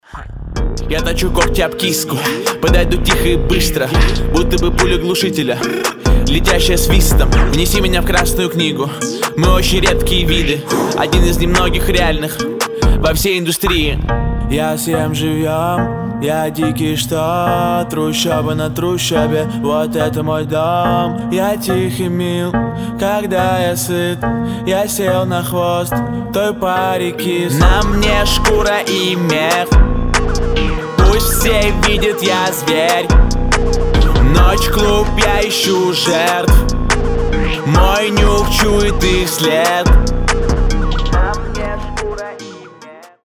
• Качество: 320, Stereo
мужской вокал
русский рэп
мощные басы
качающие